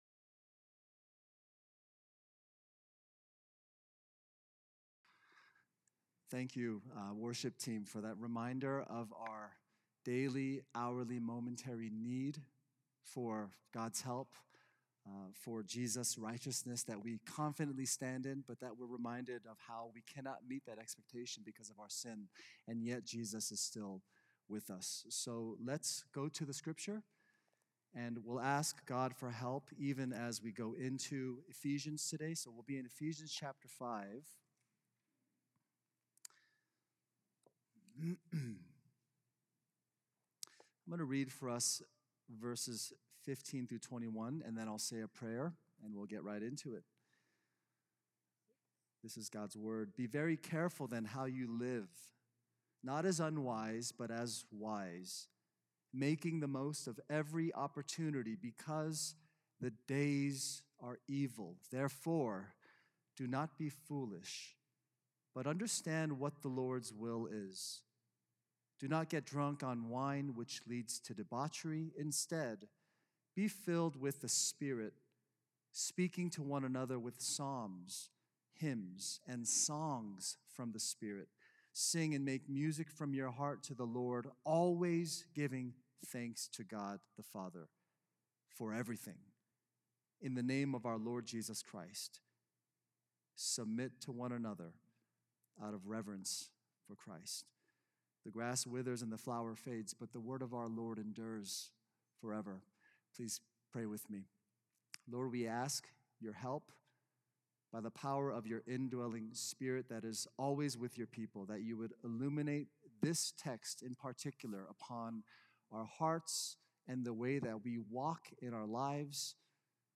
A message from the series "Sunday Services."